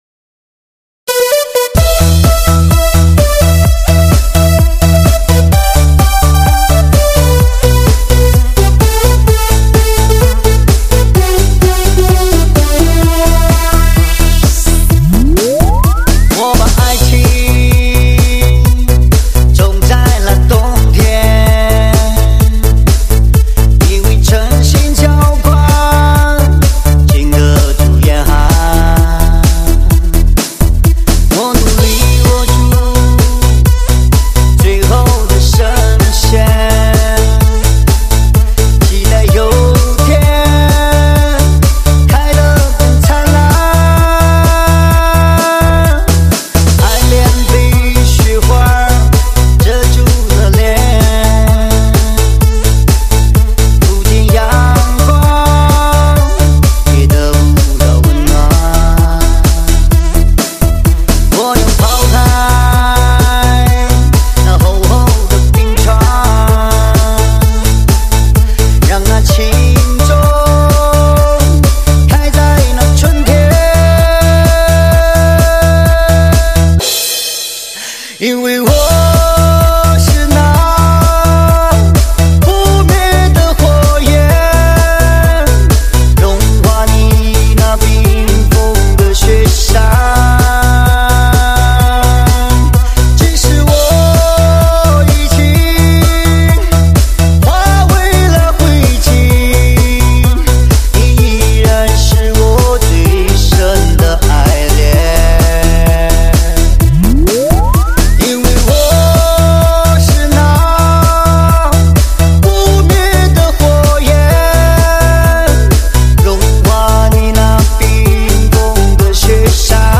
真正实现车内空间6.1Simulation 360度环绕HIFI音效